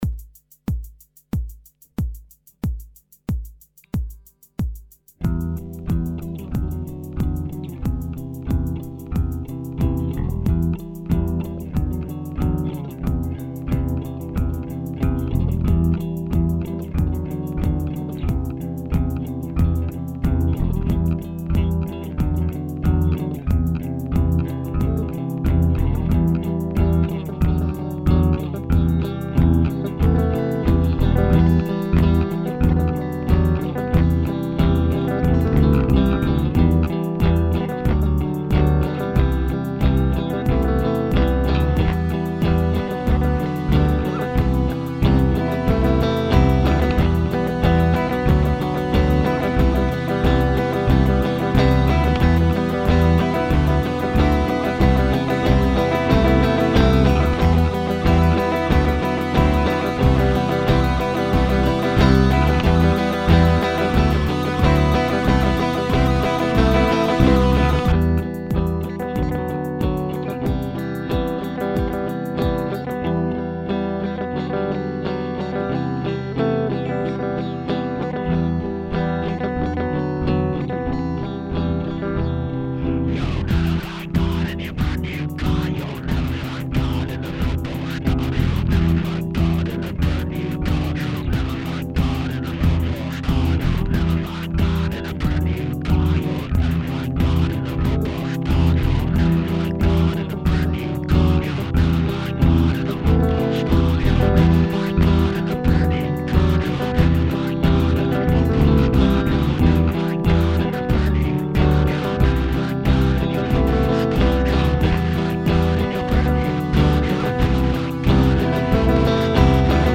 Metal was creeping back into my musicality.